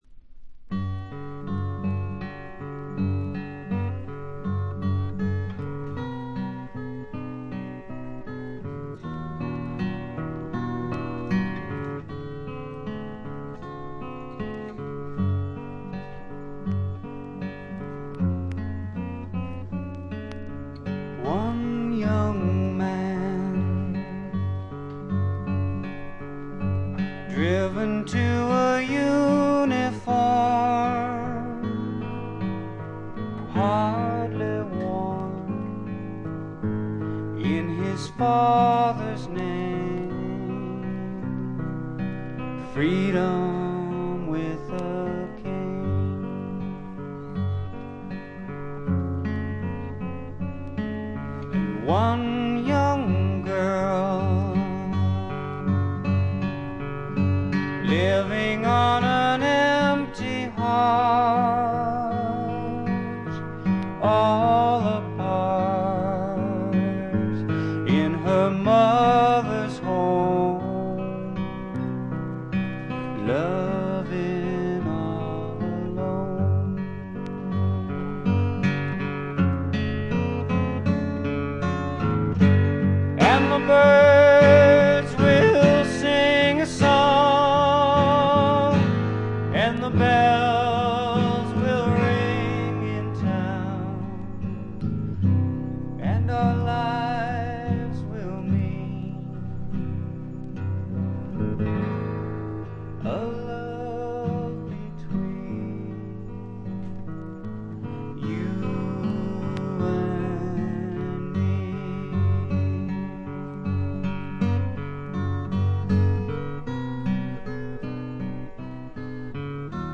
細かなチリプチのみ。
試聴曲は現品からの取り込み音源です。